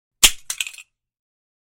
Звуки мышеловки
Звук: в ловушку попала мышка